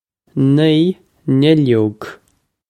nee nill-yohg
This is an approximate phonetic pronunciation of the phrase.